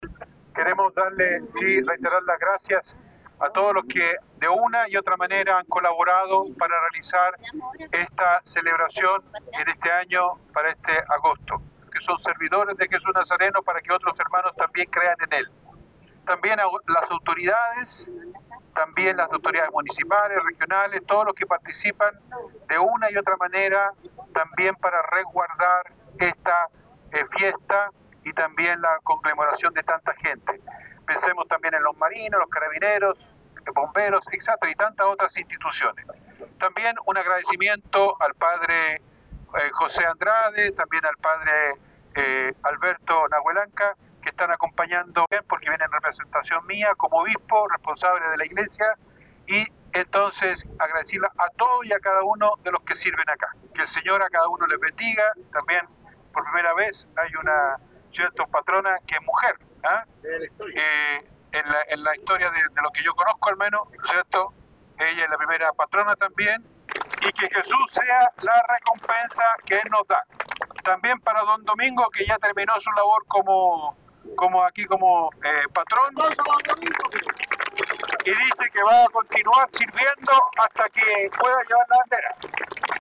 Este día especial de participación popular de las comunidades contó como es habitual con las dos eucaristías, a las 09 y media de la mañana y al mediodía, para posteriormente realizar la Procesión por la explanada del Santuario de Jesús Nazareno, presencia masiva  que fue agradecido por el Obispo de la Diócesis, Monseñor Juan María Agurto.
01-OBISPO.mp3